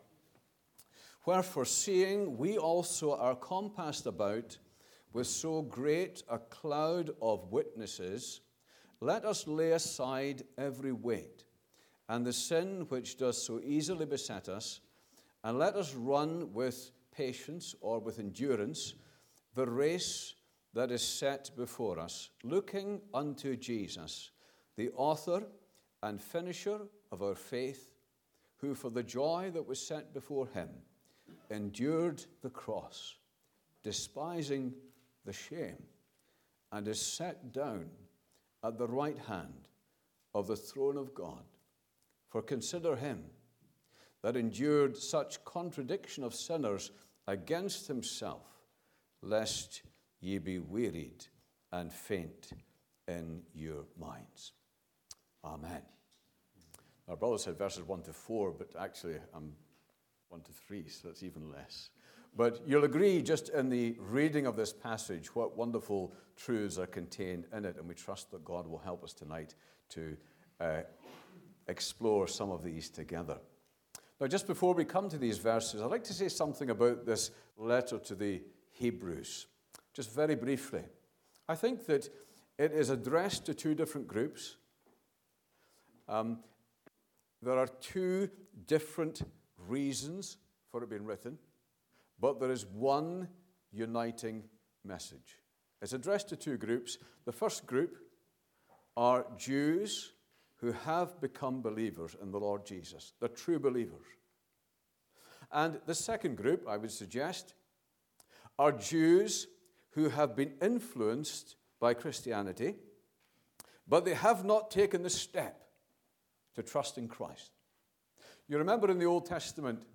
2026 Easter Conference